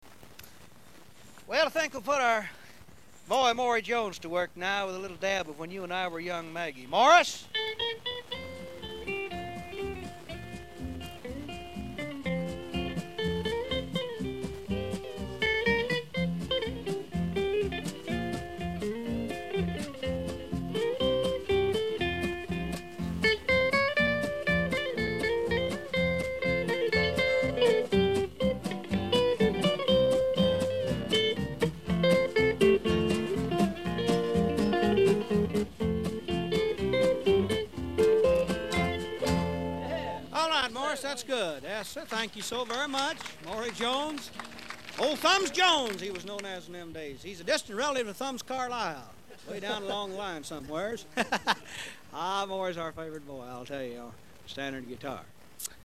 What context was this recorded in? For now, these recordings are quick and dirty conversions.